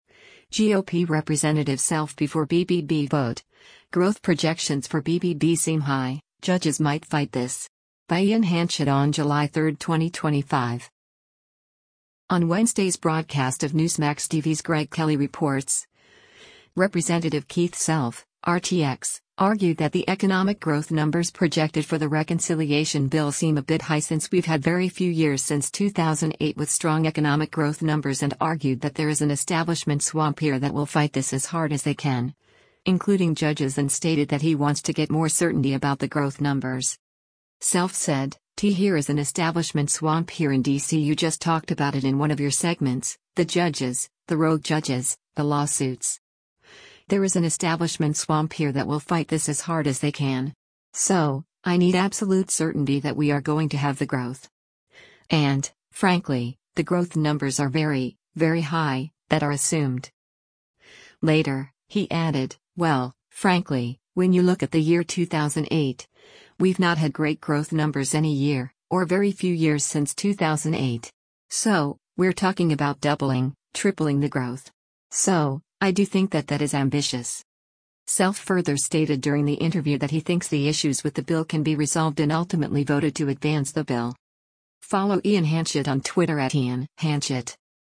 On Wednesday’s broadcast of Newsmax TV’s “Greg Kelly Reports,” Rep. Keith Self (R-TX) argued that the economic growth numbers projected for the reconciliation bill seem a bit high since we’ve had “very few years since 2008” with strong economic growth numbers and argued that “There is an establishment swamp here that will fight this as hard as they can”, including judges and stated that he wants to get more certainty about the growth numbers.
Self further stated during the interview that he thinks the issues with the bill can be resolved and ultimately voted to advance the bill.